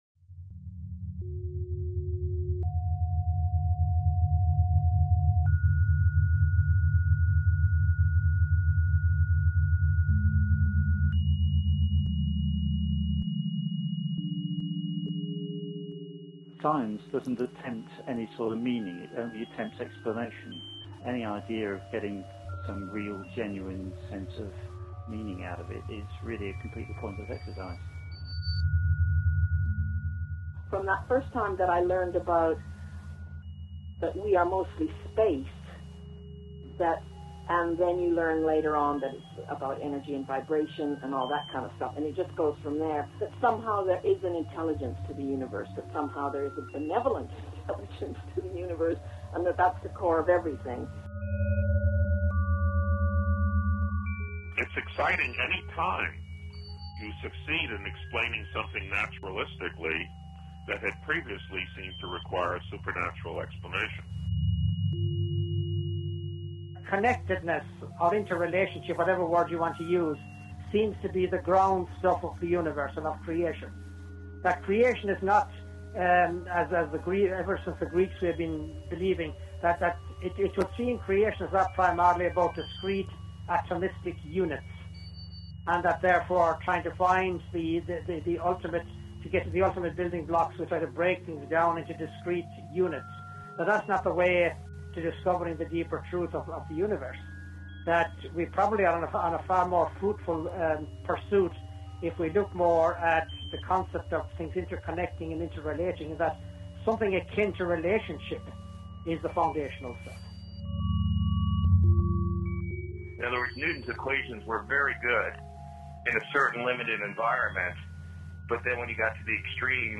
Montage of voices
Interview